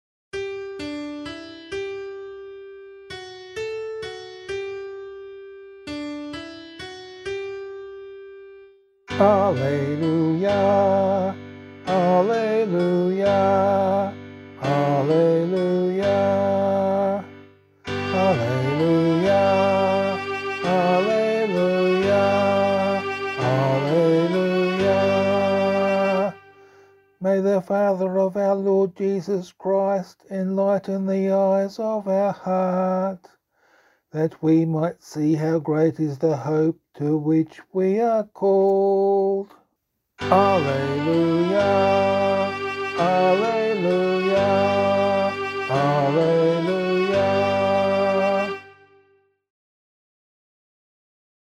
Gospel Acclamation for Australian Catholic liturgy.
062 Ordinary Time 28 Gospel A [LiturgyShare F - Oz] - vocal.mp3